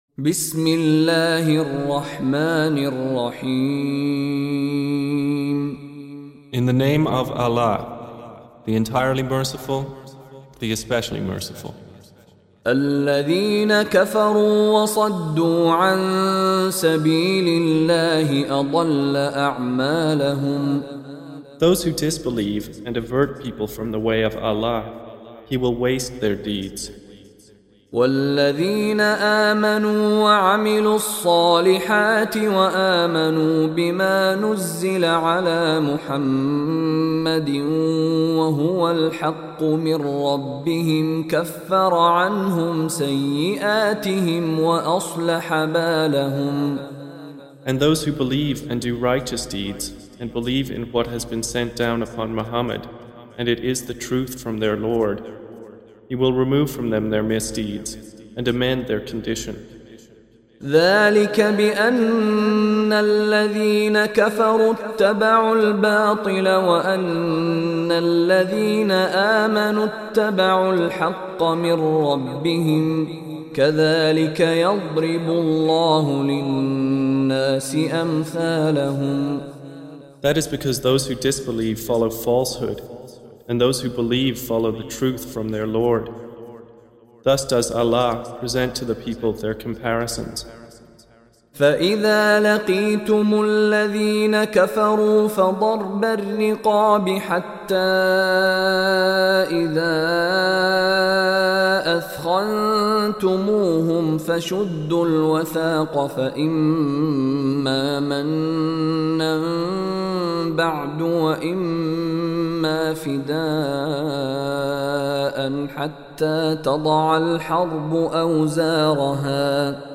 Surah Repeating تكرار السورة Download Surah حمّل السورة Reciting Mutarjamah Translation Audio for 47. Surah Muhammad or Al-Qit�l سورة محمد N.B *Surah Includes Al-Basmalah Reciters Sequents تتابع التلاوات Reciters Repeats تكرار التلاوات